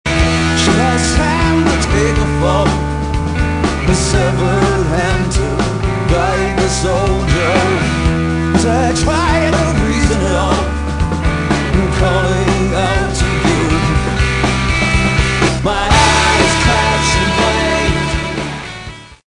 additional vocals
pedal steel